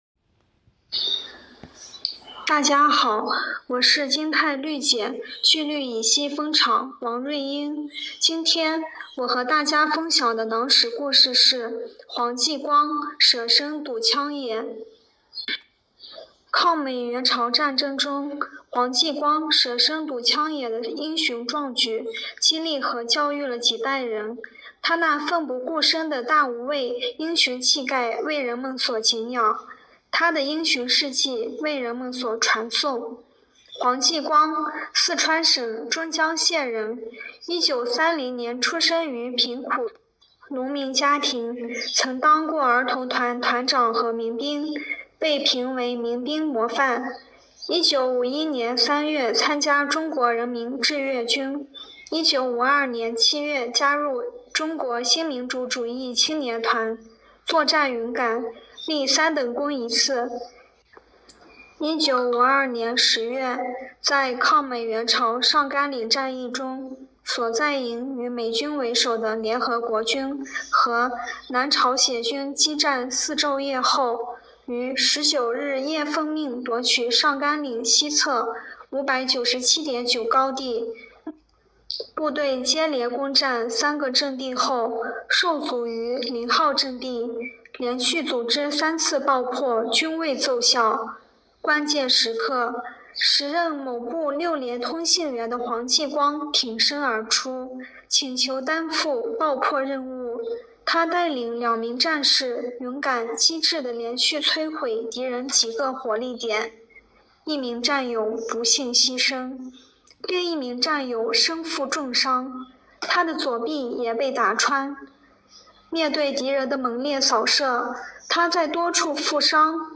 诵读者